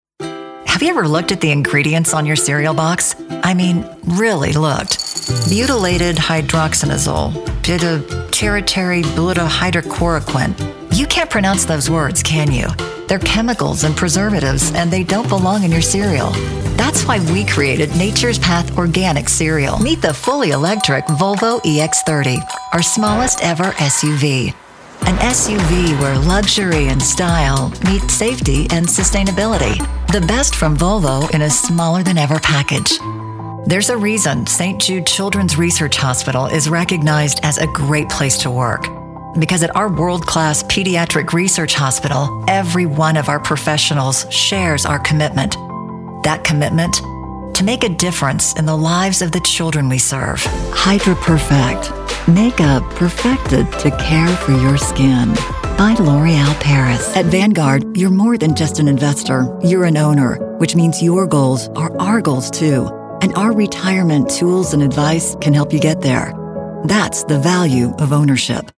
Warm, Confident, Elegant.
Commercial